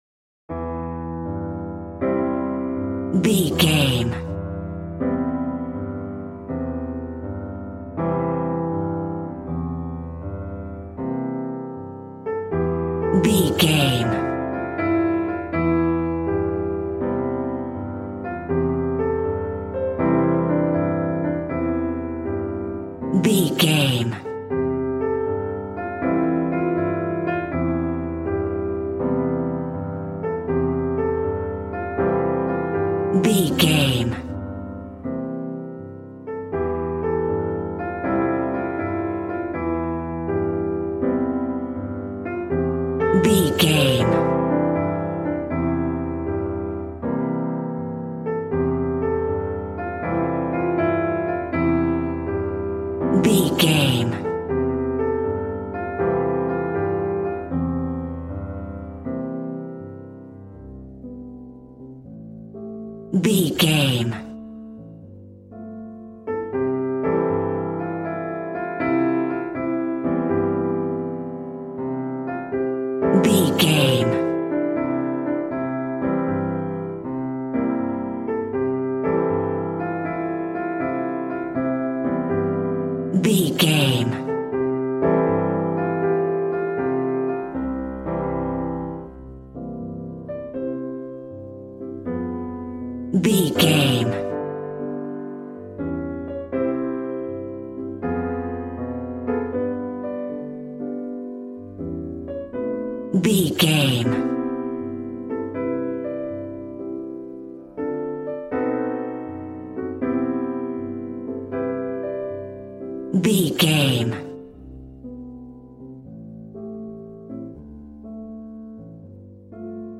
Smooth jazz piano mixed with jazz bass and cool jazz drums.,
Ionian/Major
piano
drums